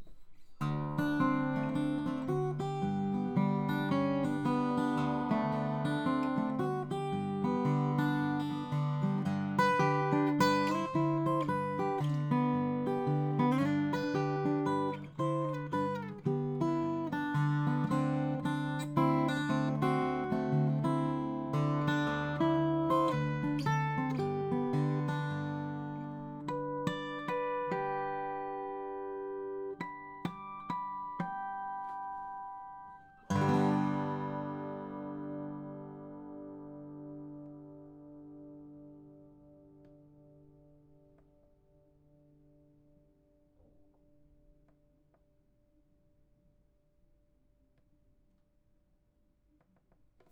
I set up a comparison by putting the H2n, the H2, and a Rode NT4 stereo mic next to each other.
The Rode was connected to my Echo Audiofire Pre8 interface.
With the recorders still running I played a bit of slack key guitar, and left a nice long “tail” at the end of the recording complete with tummy gurgles.
I trimmed off the test tone when I rendered the sample files – no one should be subjected to a 1 khz tone if they don’t have to be.
Rode NT4